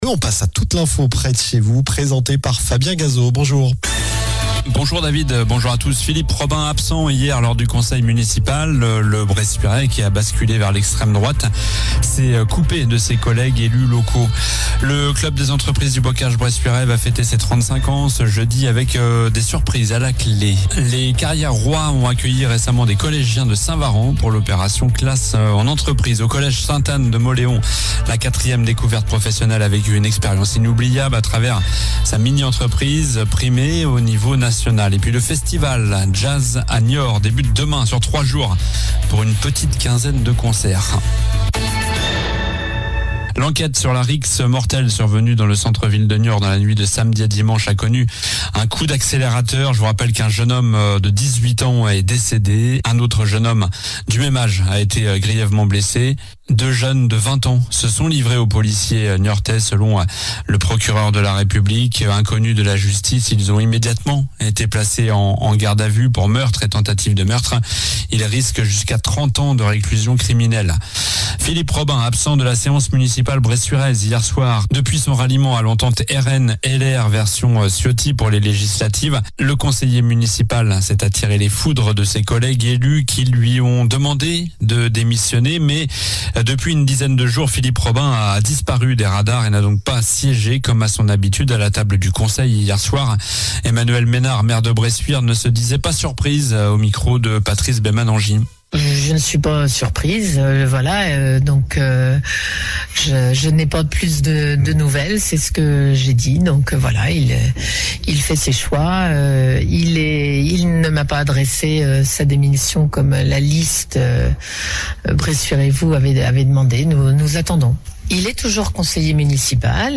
Journal du mardi 25 juin (midi)